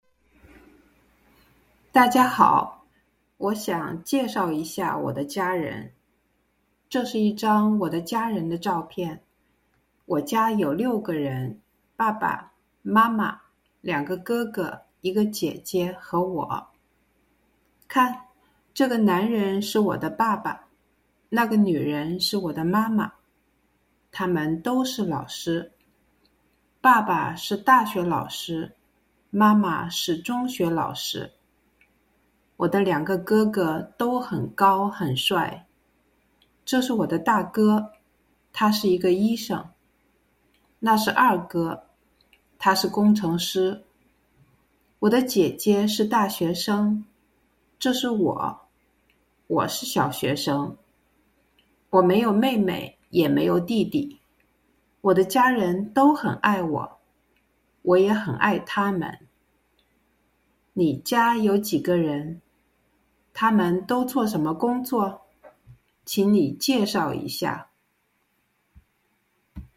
slow-speed reading:
L2D2-narrative-reading-slow.mp3